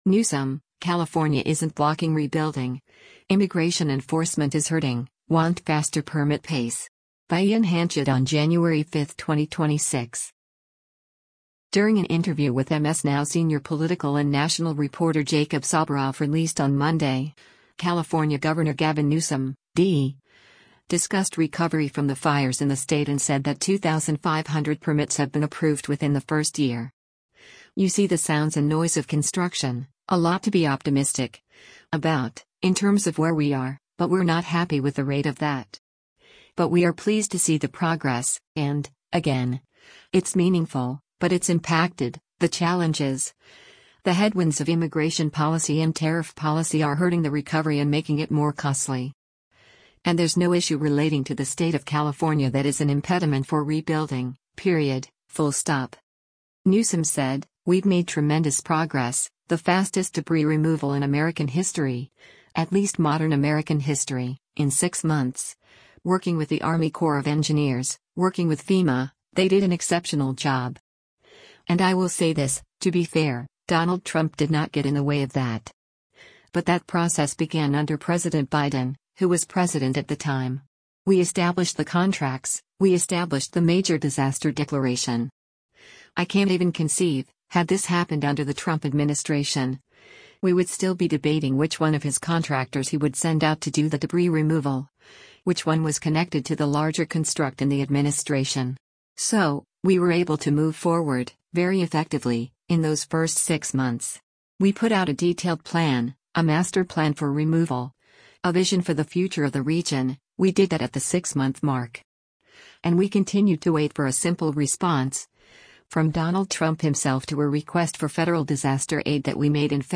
During an interview with MS NOW Senior Political and National Reporter Jacob Soboroff released on Monday, California Gov. Gavin Newsom (D) discussed recovery from the fires in the state and said that “2,500 permits have been approved within the first year. You see the sounds and noise of construction, a lot to be optimistic [about] in terms of where we are,” but “We’re not happy with the rate of that. But we are pleased to see the progress, and, again, it’s meaningful, but it’s impacted, the challenges, the headwinds of immigration policy and tariff policy are hurting the recovery and making it more costly.” And “There’s no issue relating to the state of California that is an impediment for rebuilding, period, full stop.”